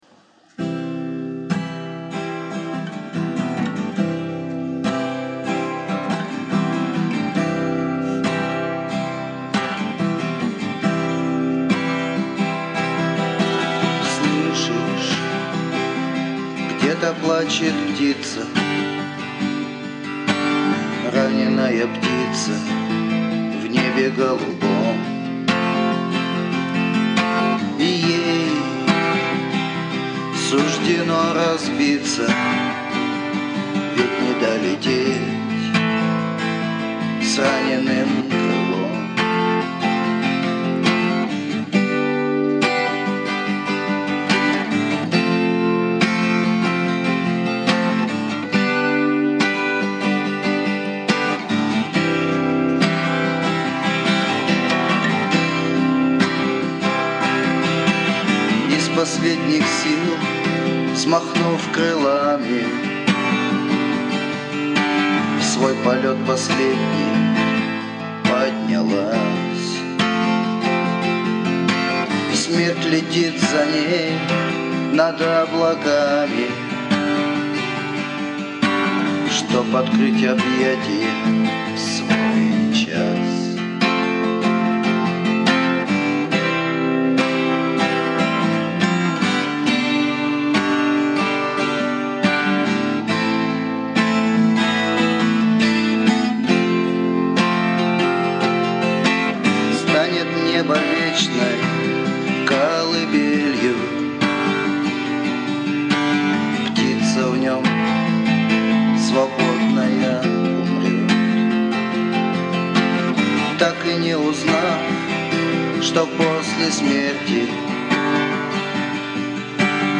Песня
Авторские песни